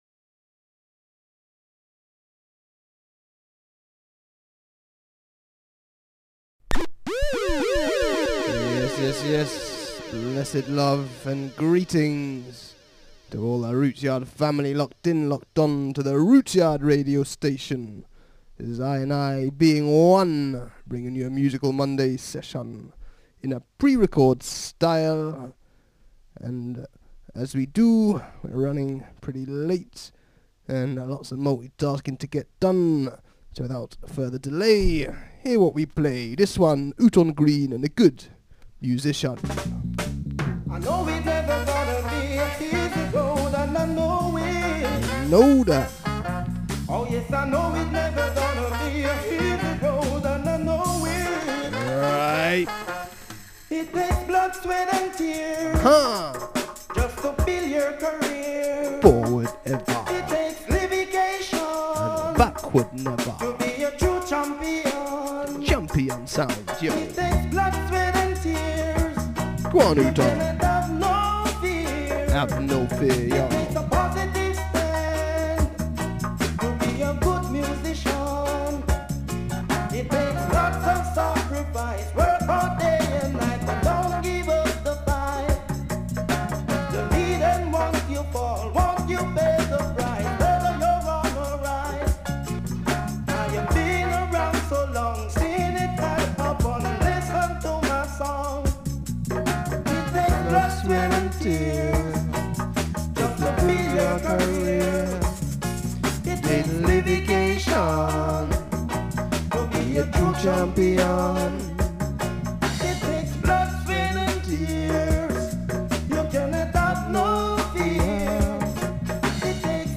on the 26th December 2022 with some added Piano flow